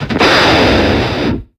Cries
KROOKODILE.ogg